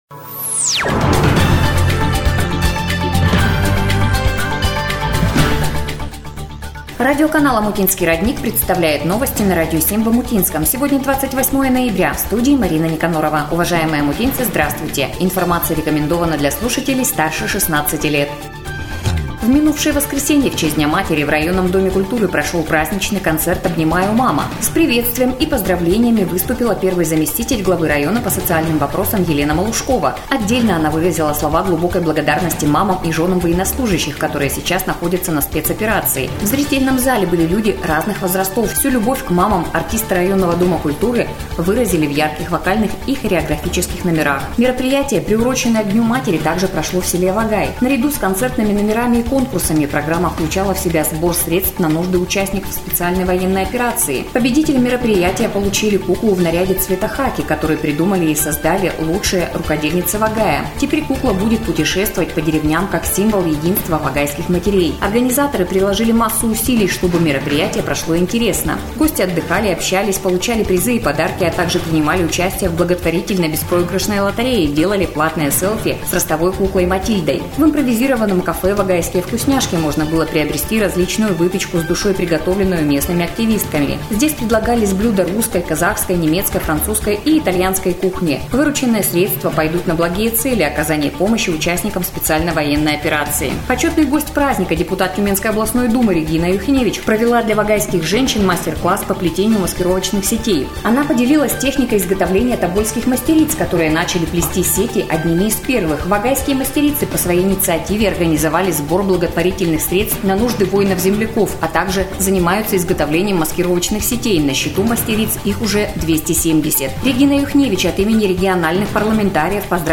Эфир радиоканала "Омутинский родник" от 28 ноября 2023 года.